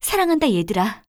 cleric_f_voc_social_04.wav